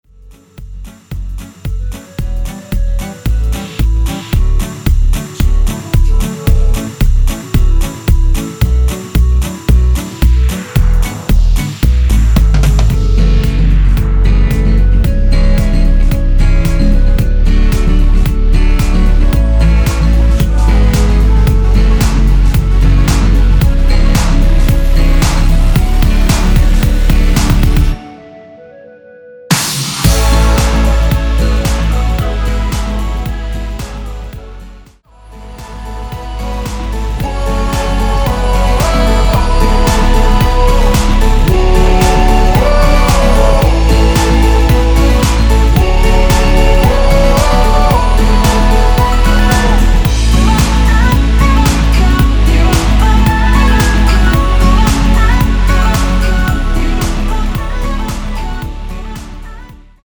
키 A 가수